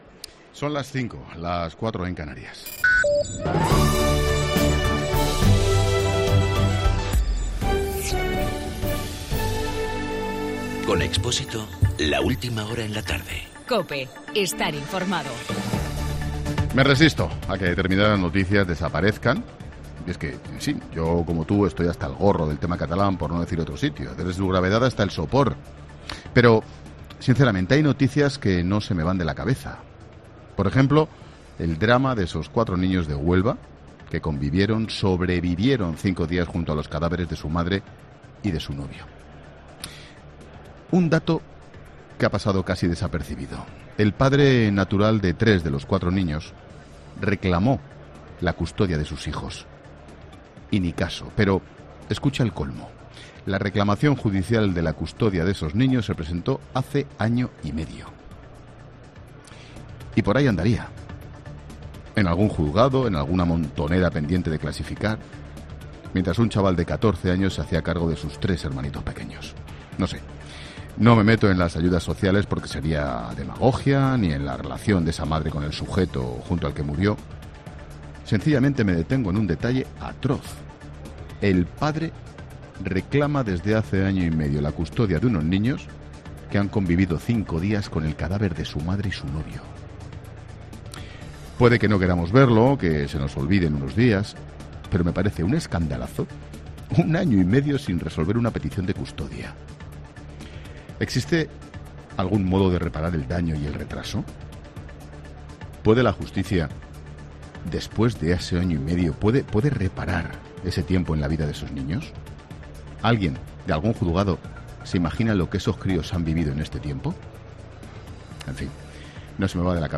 Monólogo de Expósito
Ángel Expósito habla en su monólogo de las 17 horas sobre la custodia de los niños de Huelva que tuvieron que convivir durante una semana con los cadáveres de su madre y su pareja.